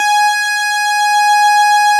Added synth instrument
snes_synth_068.wav